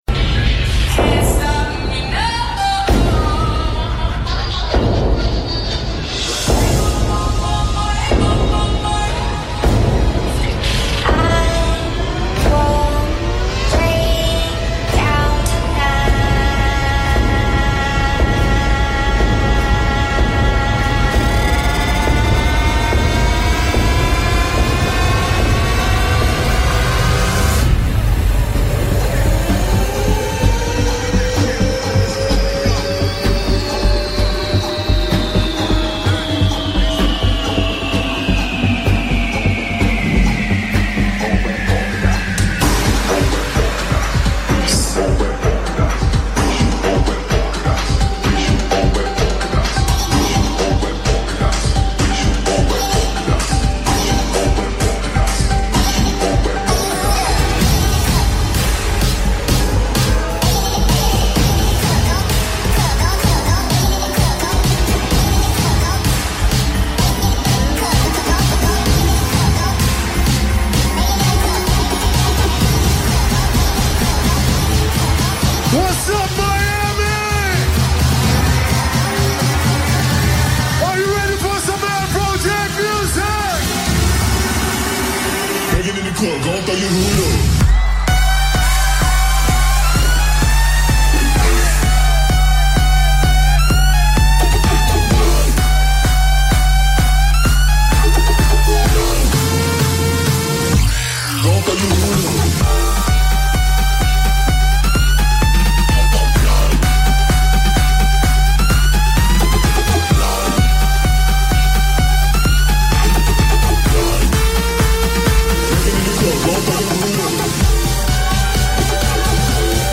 Also find other EDM Livesets, DJ
Liveset/DJ mix